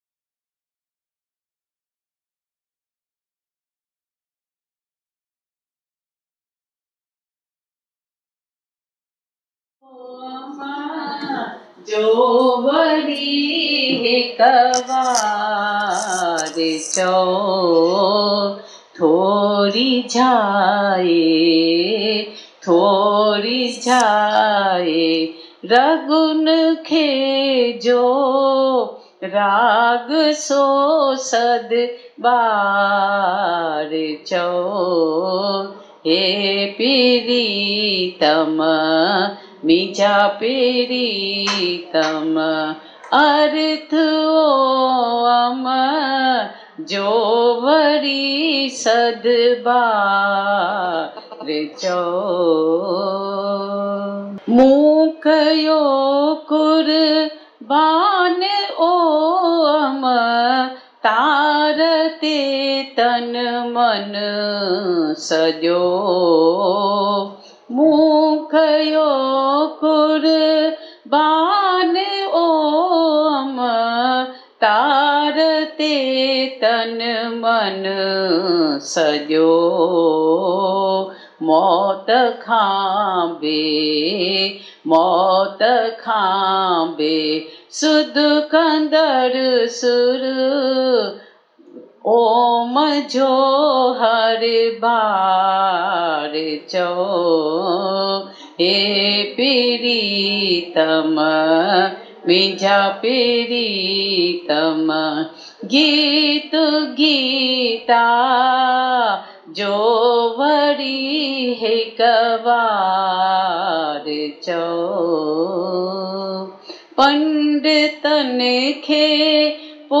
Bhajan